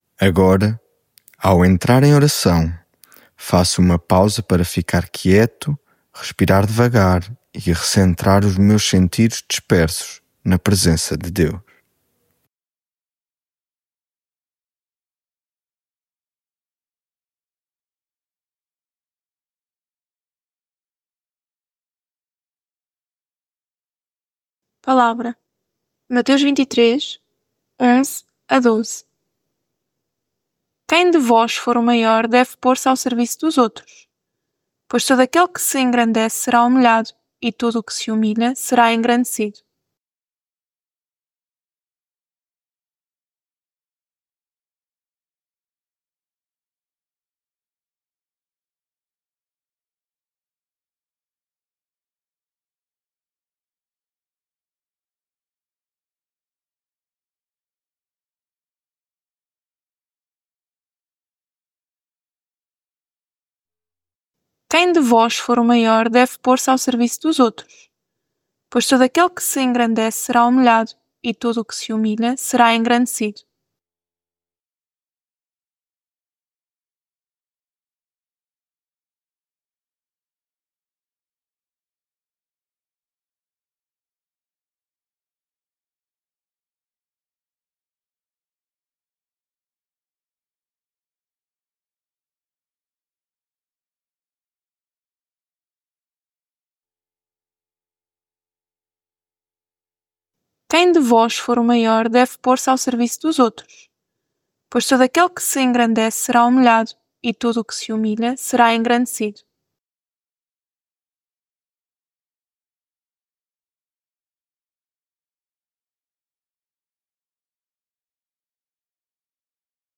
lectio divina Agora, ao entrar em oração, faço uma pausa para ficar quieto, respirar devagar e recentrar os meus sentidos dispersos na presença de Deus....
Devocional 14 Tu amas-me?